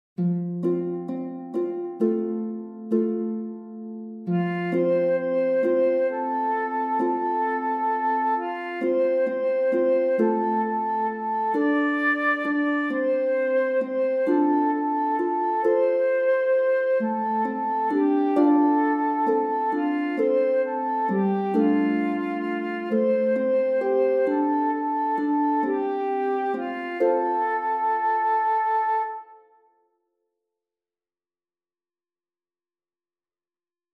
De engelen zingen 'Beheerser van 't water'